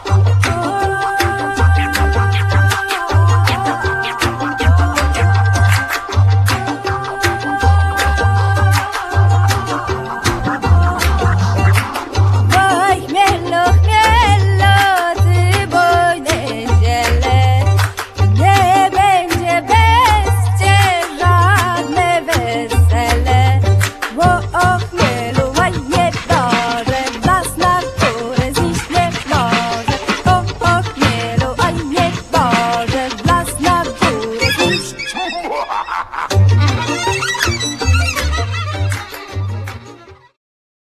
wiolonczela cello
cymbały dulcimer
nyckelharpa